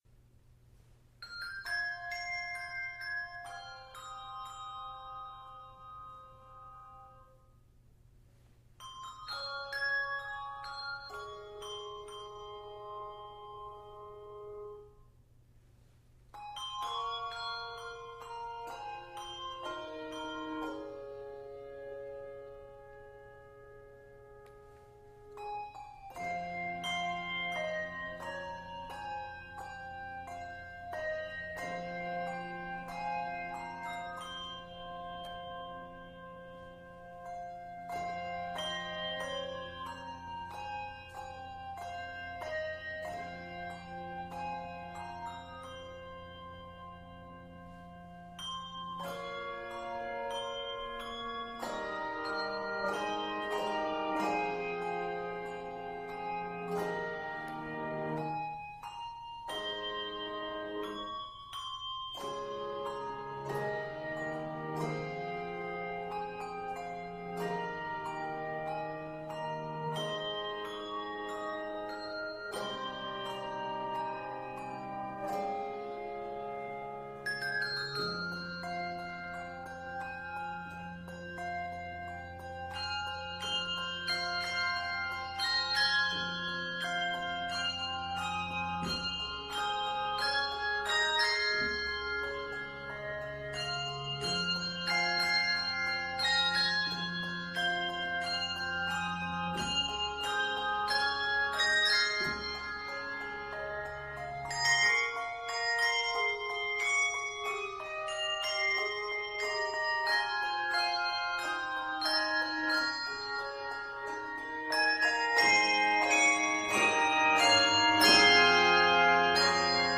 handbells
Octaves: 3-7